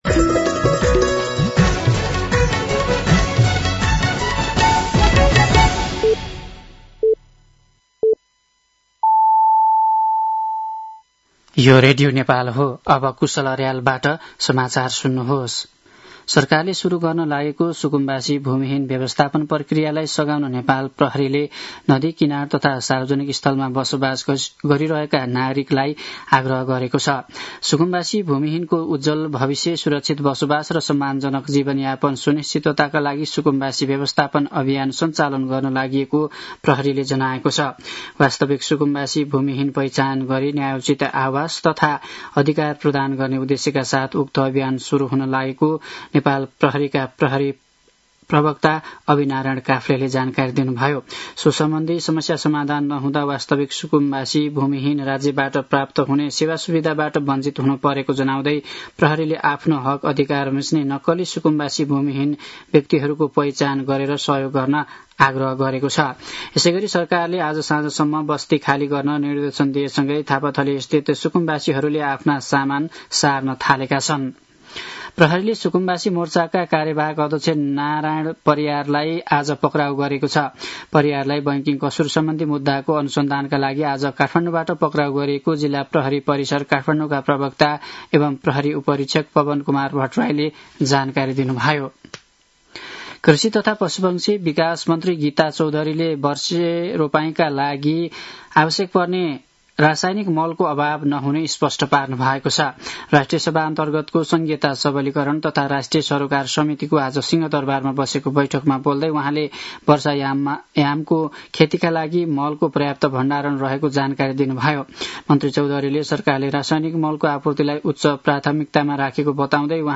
साँझ ५ बजेको नेपाली समाचार : ११ वैशाख , २०८३
5.-pm-nepali-news.mp3